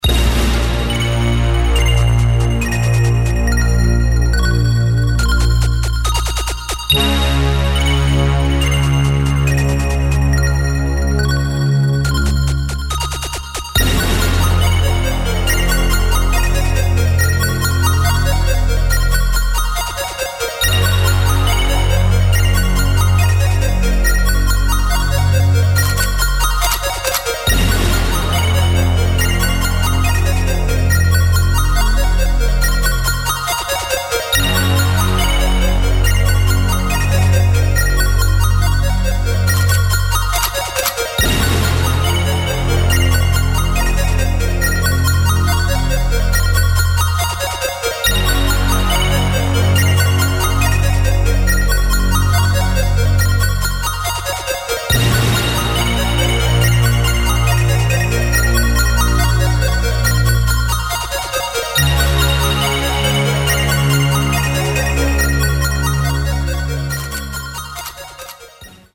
[ DUBSTEP | BASS | EXPERIMENTAL ]